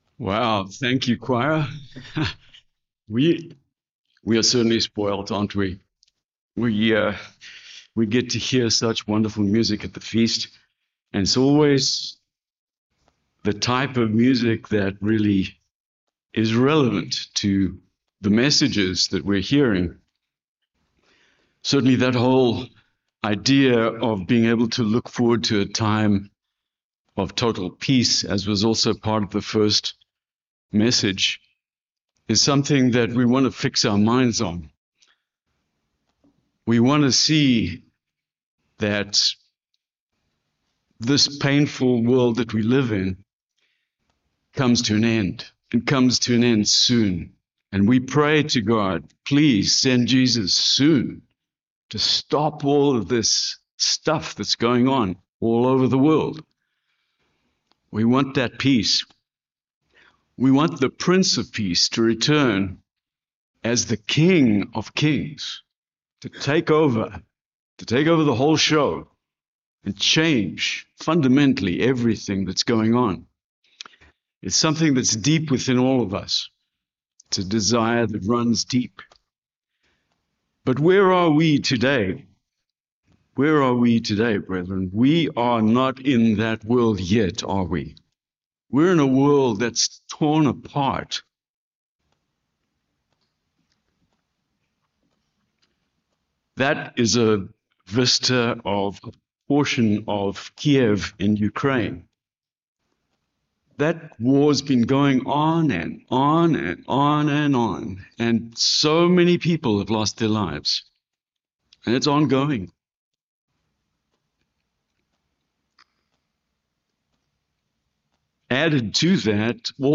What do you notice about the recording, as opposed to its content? Given in Aransas Pass, Texas